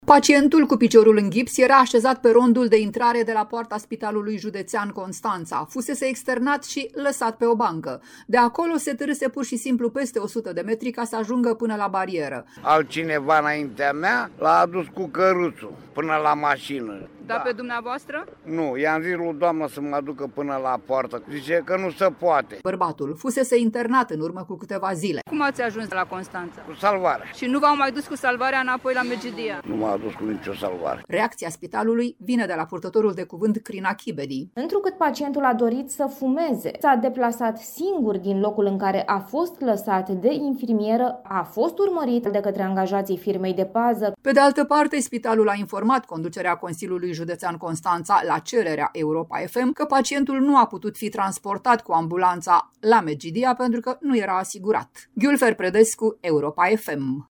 Pacientul, cu piciorul în ghips, era așezat pe rondul de intrare de la poarta Spitalului Județean de Urgență Constanța